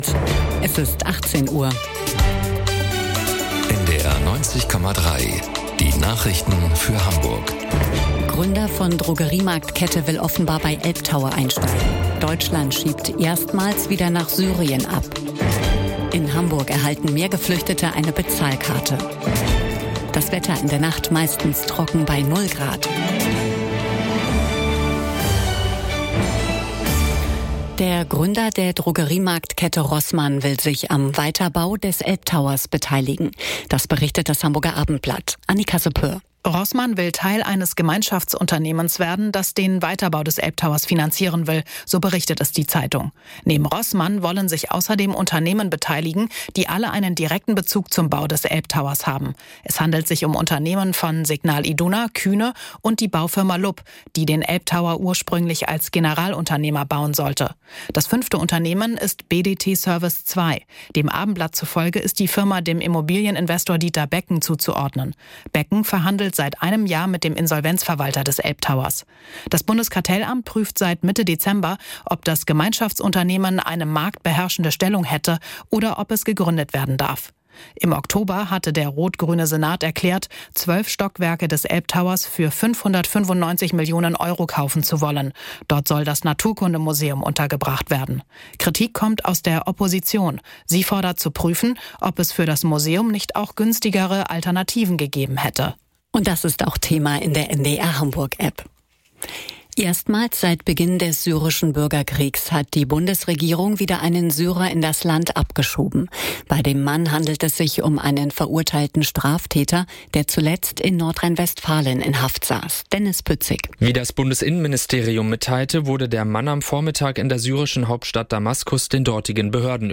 Aktuelle Ereignisse, umfassende Informationen: Im Nachrichten-Podcast von NDR 90,3 hören Sie das Neueste aus Hamburg und der Welt.